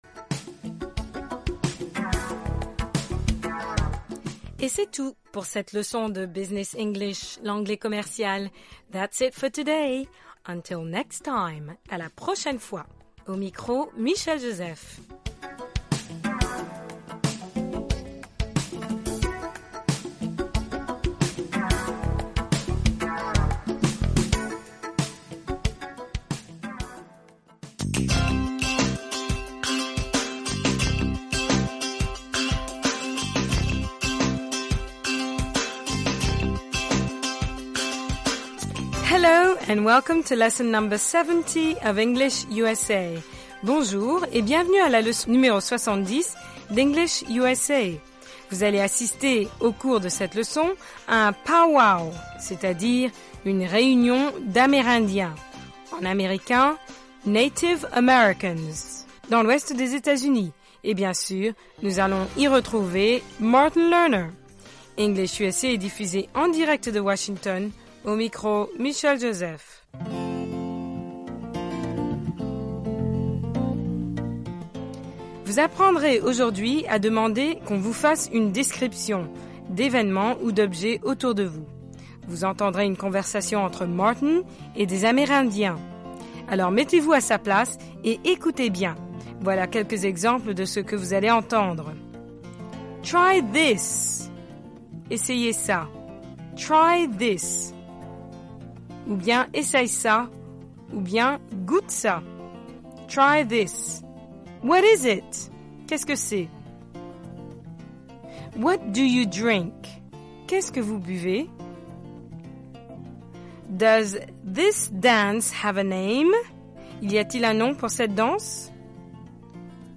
RCA: Reportage Special